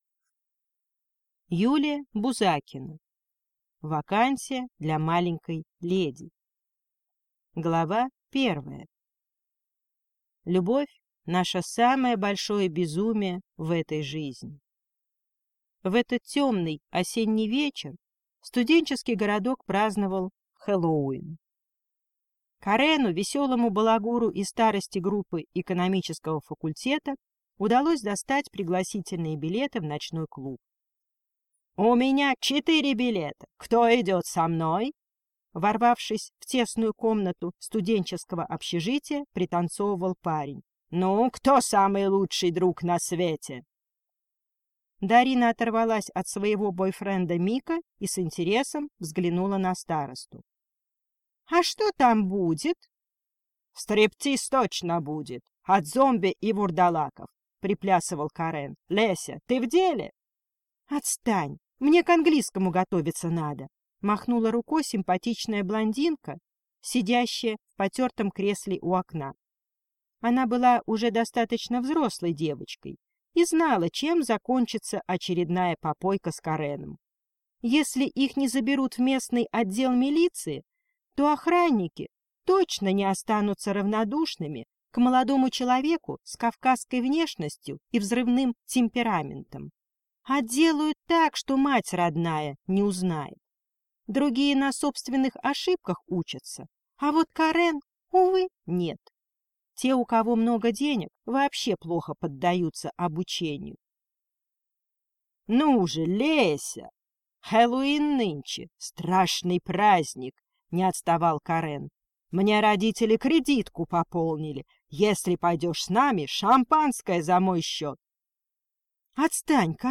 Аудиокнига Вакансия для маленькой леди | Библиотека аудиокниг